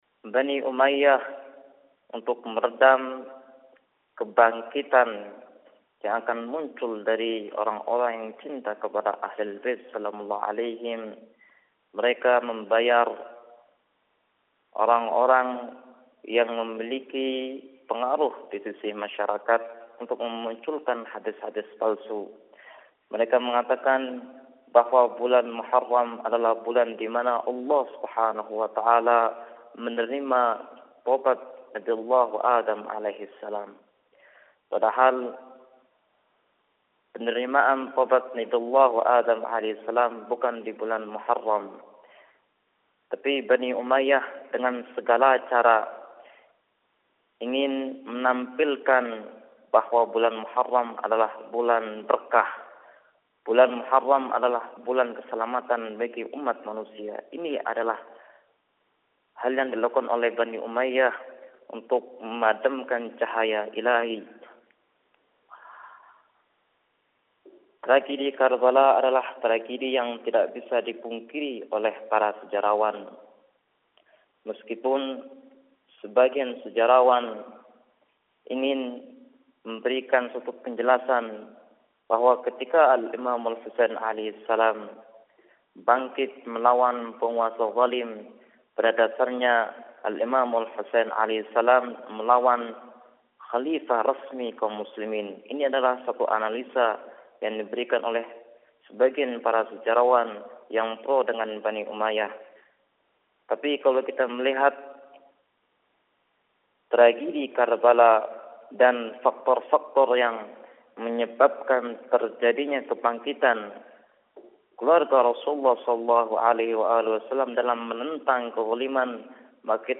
Ceramah